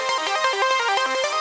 SaS_Arp05_170-C.wav